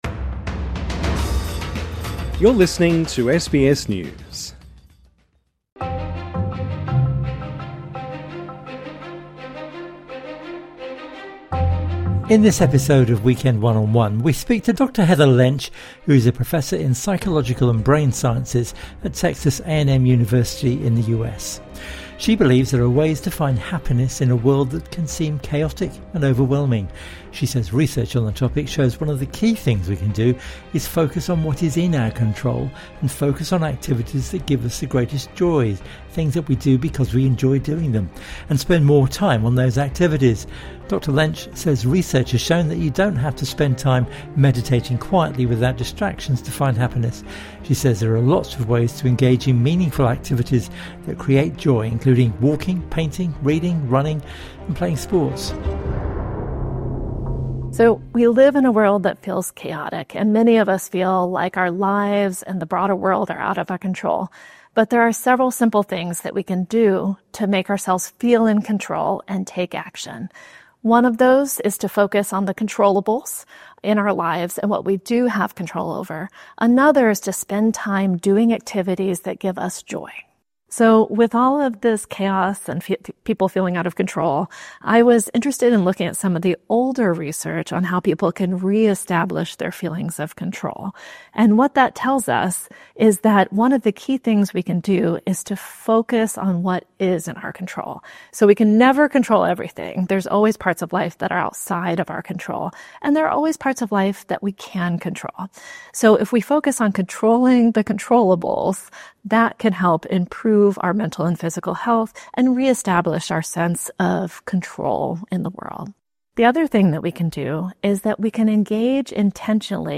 INTERVIEW: Finding joy in a world of chaos